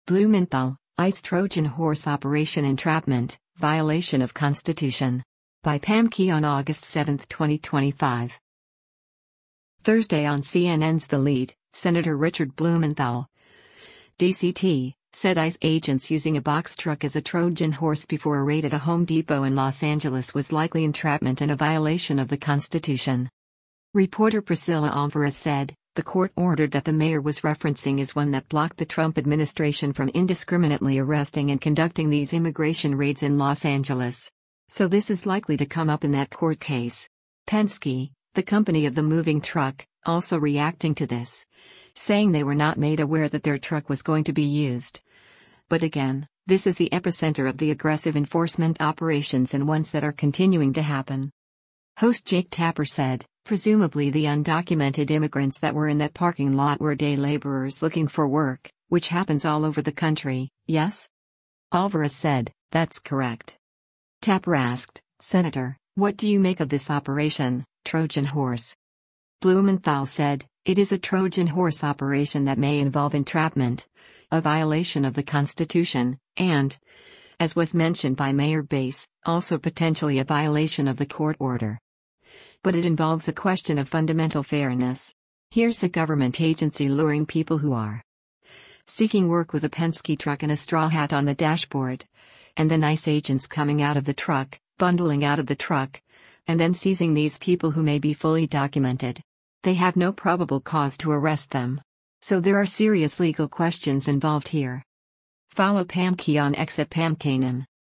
Thursday on CNN’s “The Lead,” Sen. Richard Blumenthal (D-CT) said ICE agents using a box truck as a ‘Trojan Horse” before a raid at a Home Depot in Los Angeles was likely “entrapment” and a violation of the Constitution.